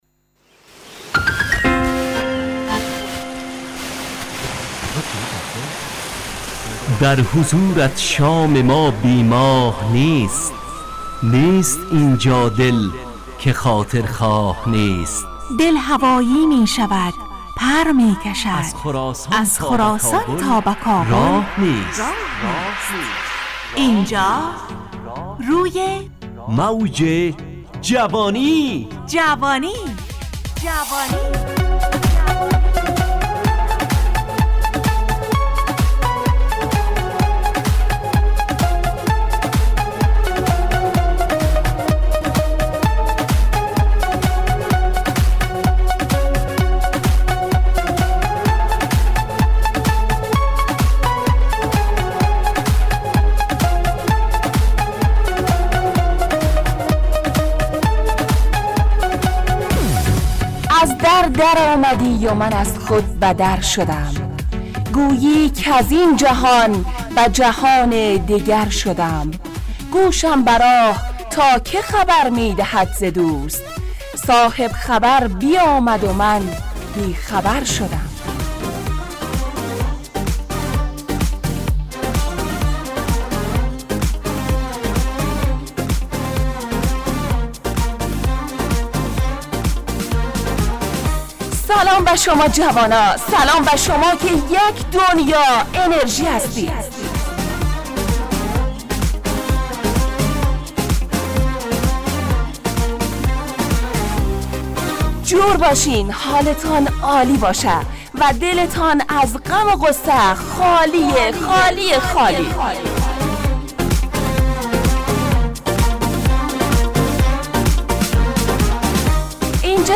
روی موج جوانی، برنامه شادو عصرانه رادیودری.
همراه با ترانه و موسیقی مدت برنامه 70 دقیقه . بحث محوری این هفته (خبر) تهیه کننده